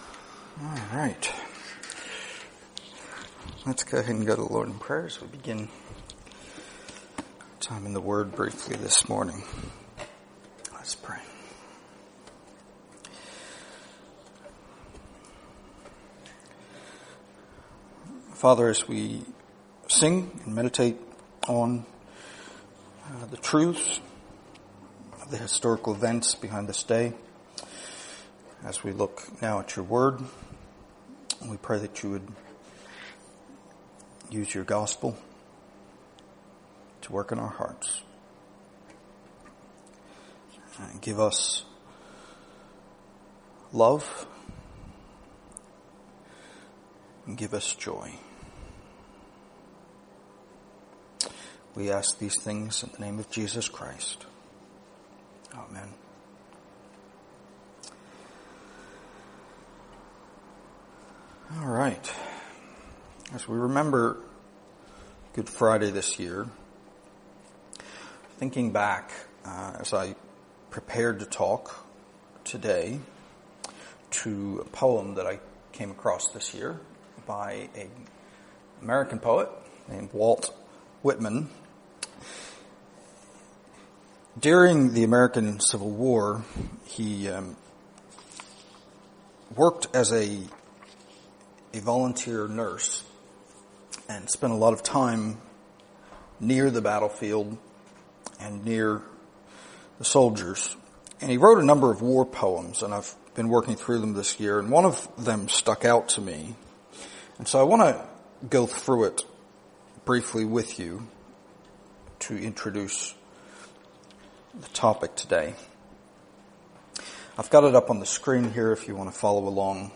Series: Stand Alone Sermons